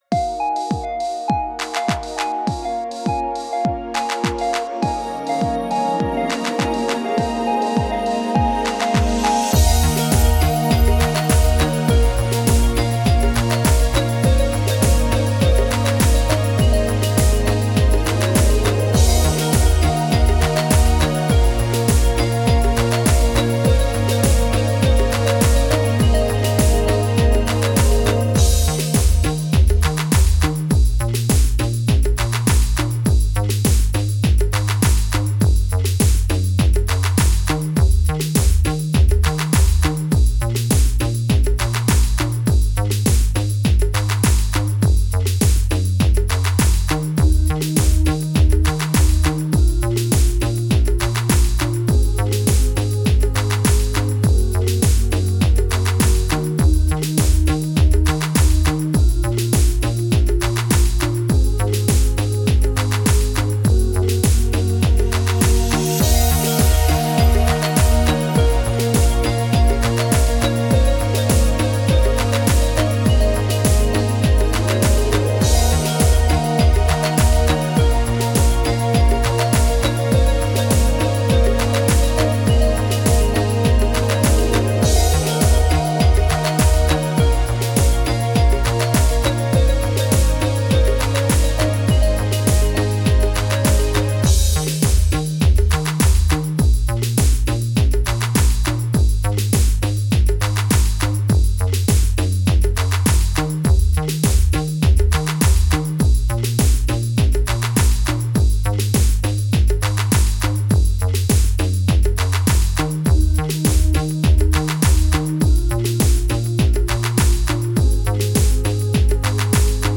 Übungsaufnahmen - Sweet Harmony
Sweet Harmony (Playback)